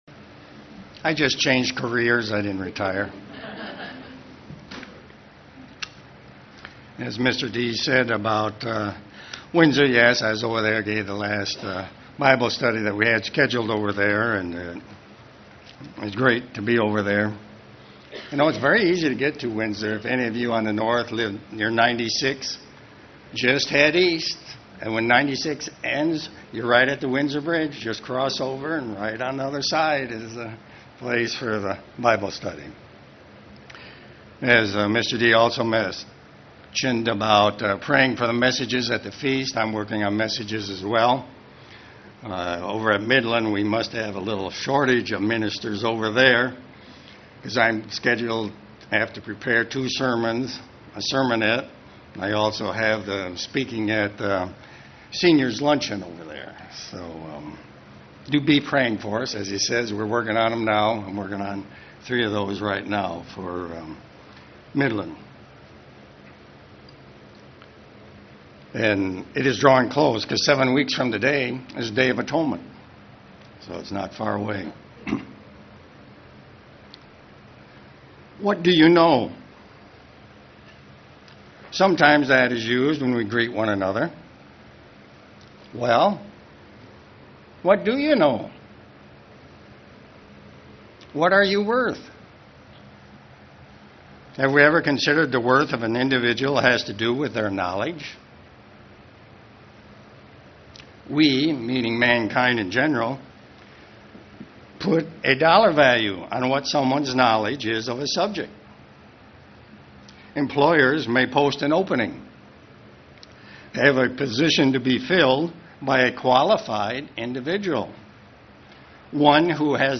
Given in Ann Arbor, MI
UCG Sermon Studying the bible?